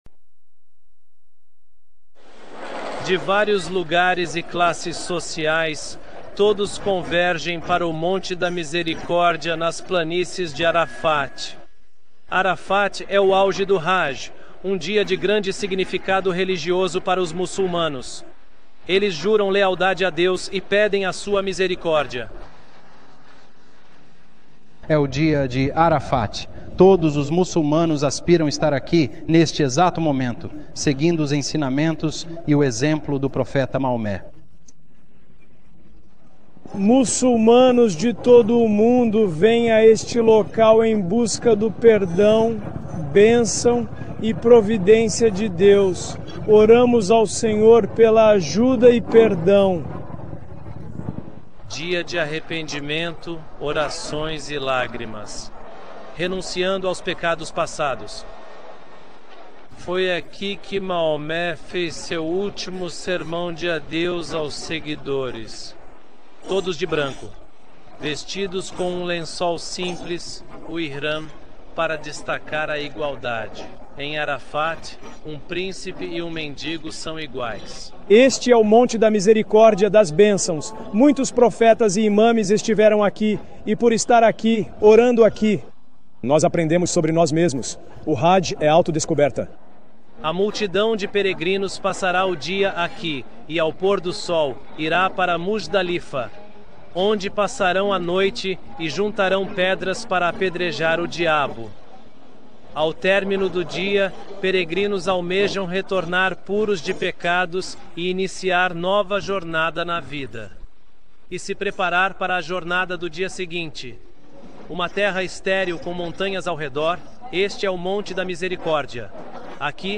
Descrição: Este vídeo é uma cobertura jornalística da Al-Jazeera sobre a virtude do Hajj.